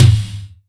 Index of /90_sSampleCDs/300 Drum Machines/Akai MPC-500/1. Kits/Garage Kit